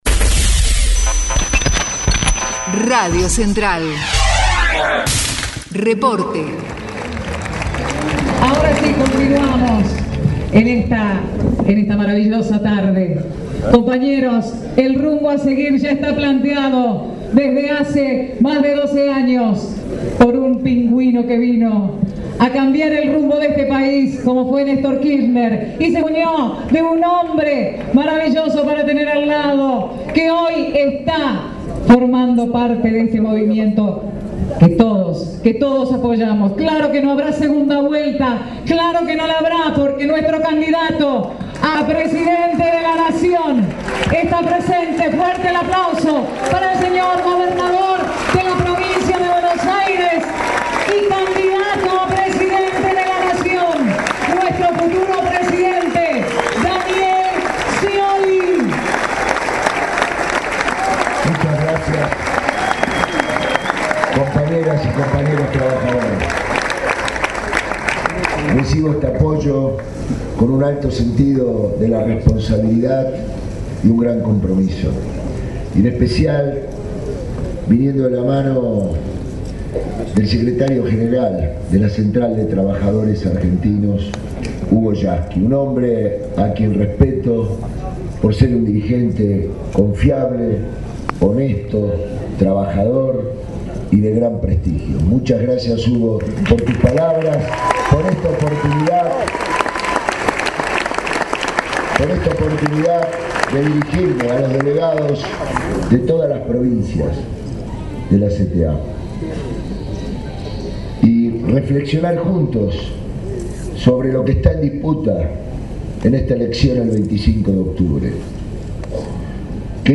DANIEL SCIOLI EN EL PLENARIO DE LA CTA